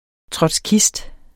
Udtale [ tʁʌdsˈkisd ]